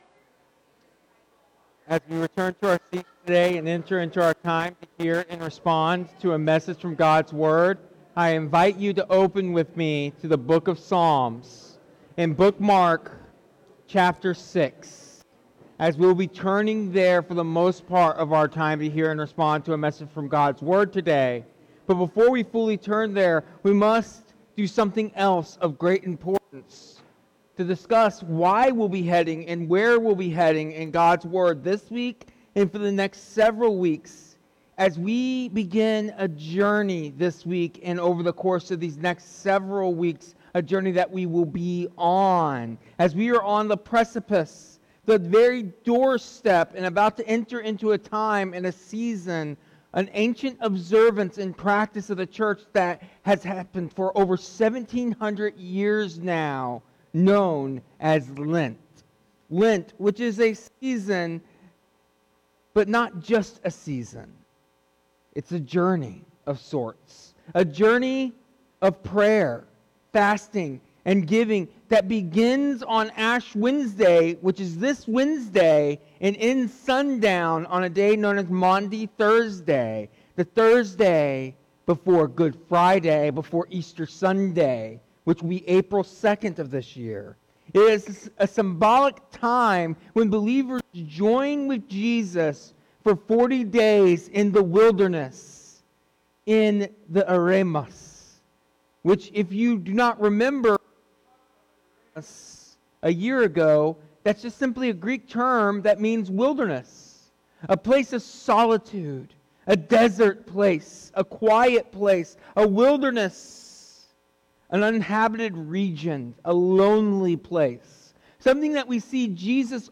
This sermon introduces the season of Lent and the journey we will take as a family of families through the Penitential Psalms this year. Lent is a sacred journey into the “eremos,” the wilderness place of testing, prayer, and dependence on God.